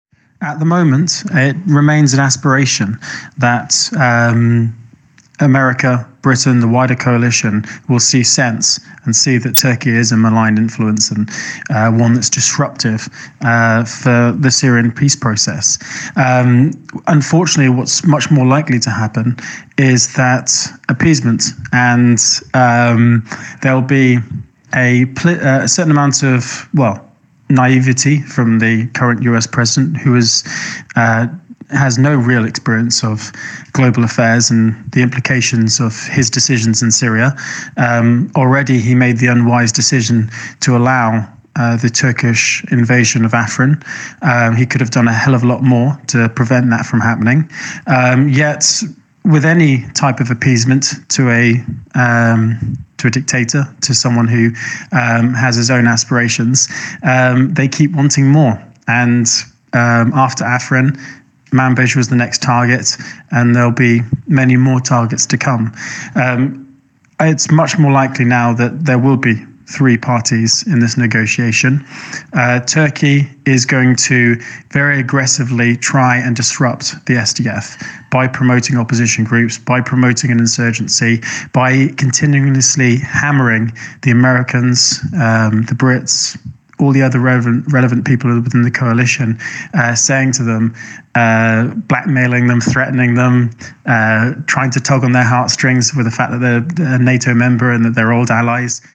The Canary spoke to a former fighter about what this means